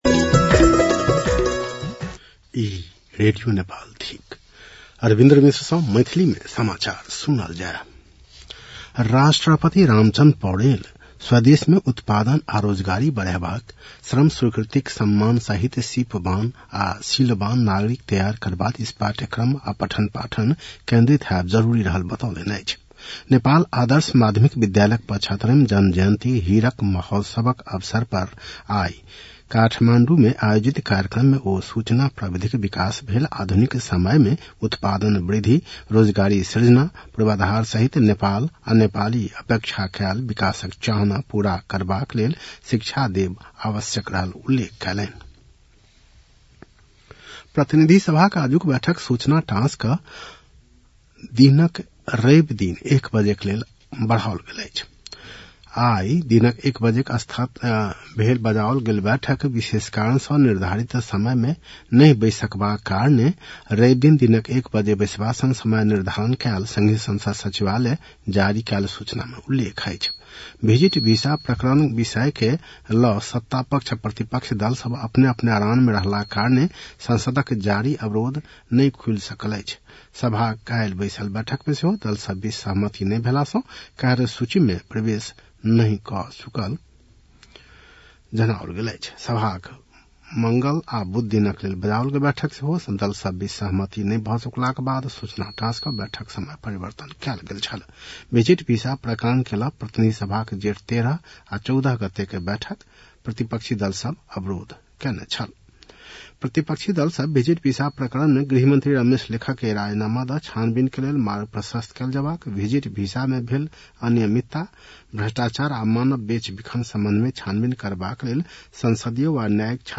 An online outlet of Nepal's national radio broadcaster
मैथिली भाषामा समाचार : २३ जेठ , २०८२